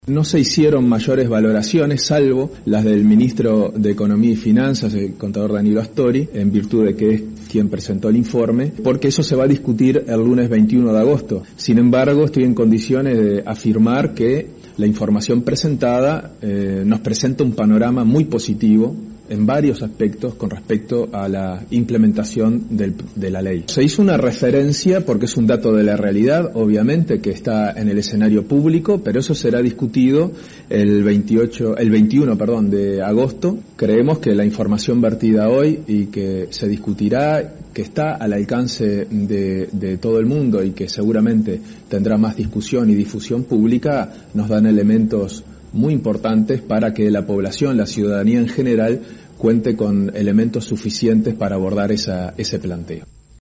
El vocero fue el Prosecretario Andrés Roballo quien manifestó: